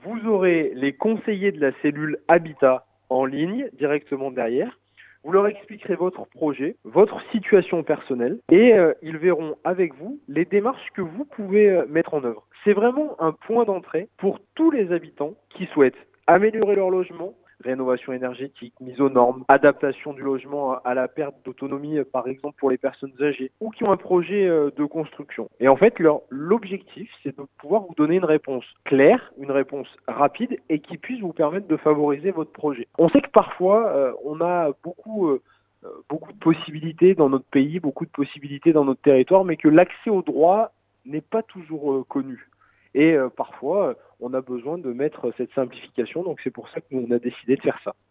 Alors quelles informations les conseillers de cette « cellule habitat » au bout du fil pourront-ils donner ? Mise en situation.